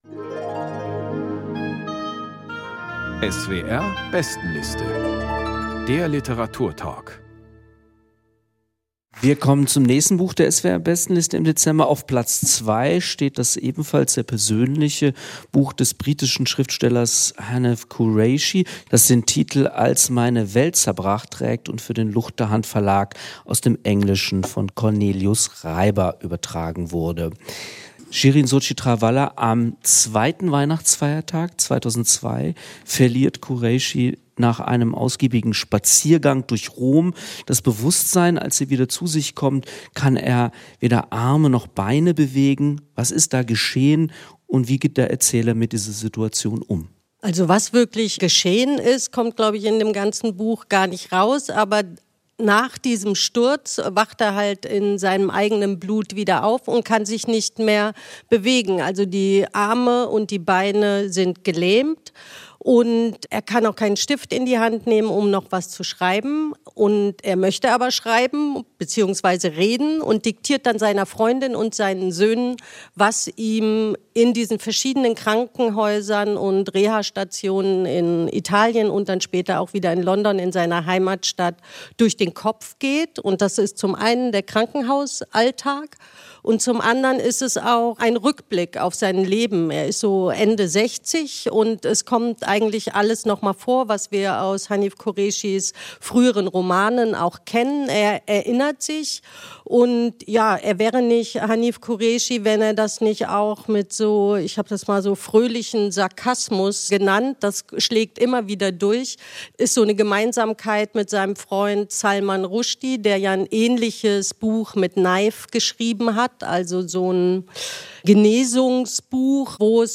Hanif Kureishi: Als meine Welt zerbrach | Lesung und Diskussion ~ SWR Kultur lesenswert - Literatur Podcast